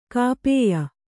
♪ kāpēya